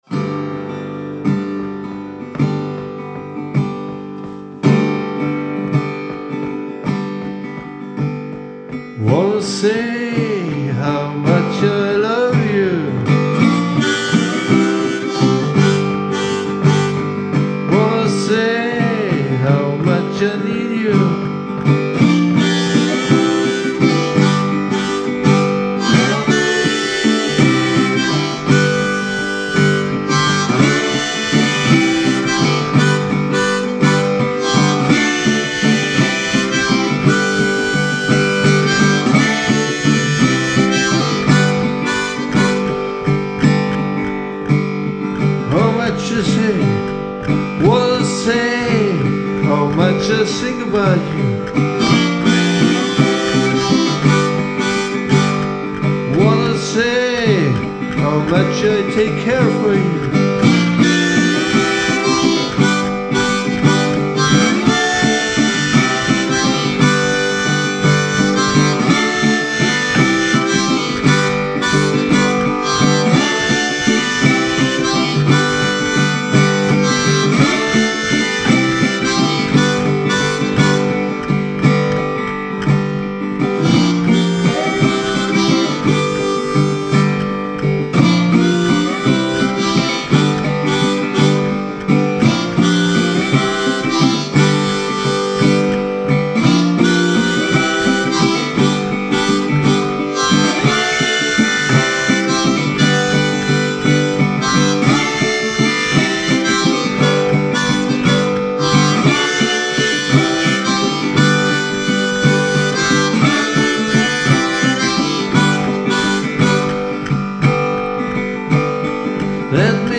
Western guitar with Vocals, epic.